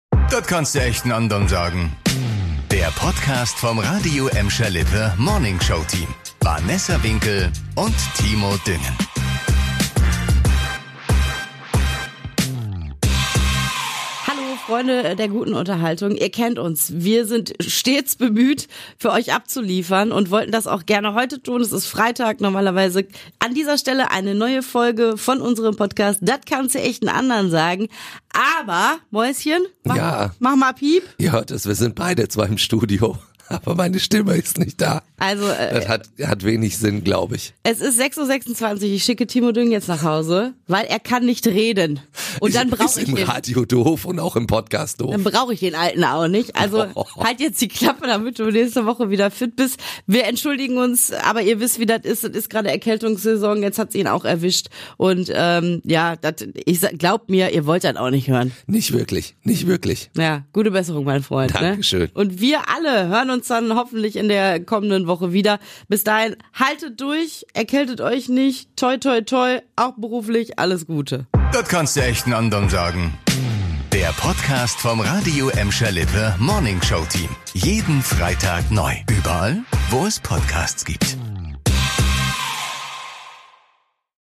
erwischt und die Erkältung hat ihm die Stimme genommen.